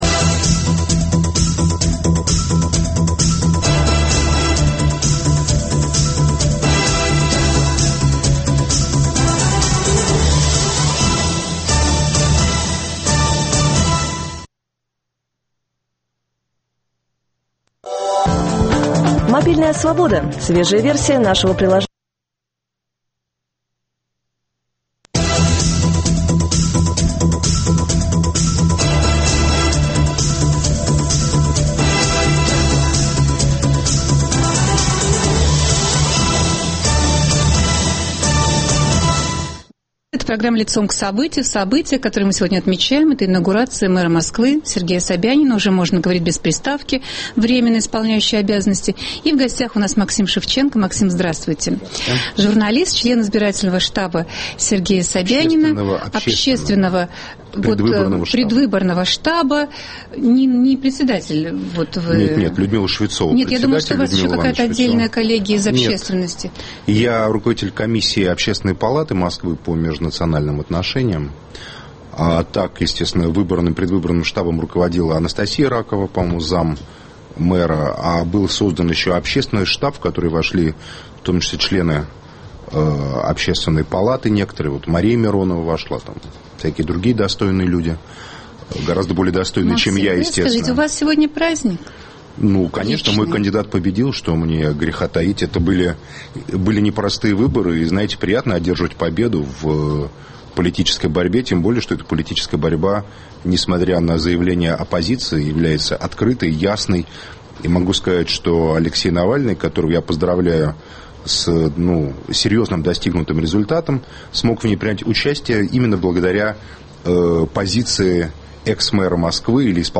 Гость студии - член предвыборного штаба Сергея Собянина и его доверенное лицо журналист Максим Шевченко.